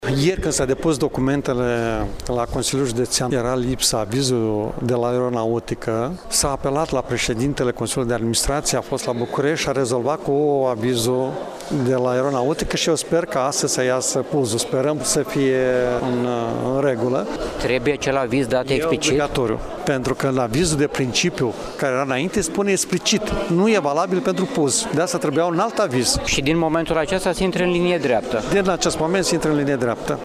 Preşedintele Consiliului Judeţean, Maricel Popa:
16-mar-rdj-17-Maricel-Popa-aviz.mp3